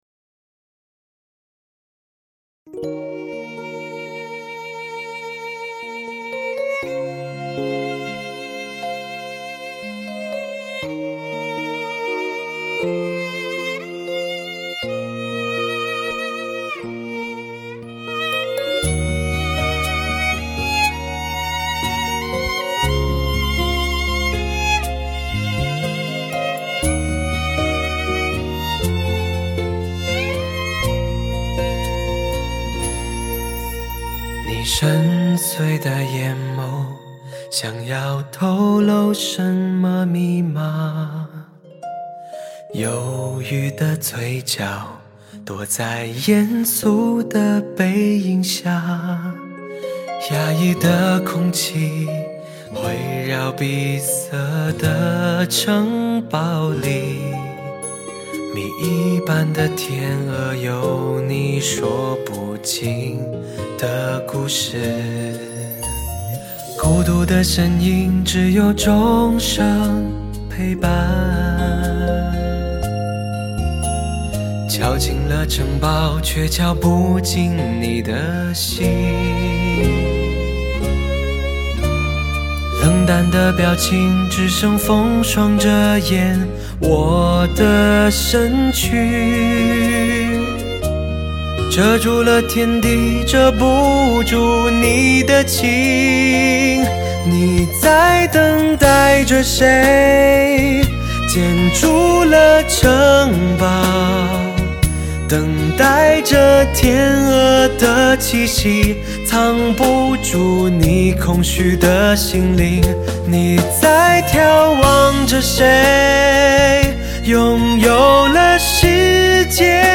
史上最HI-FI震撼的发烧靓声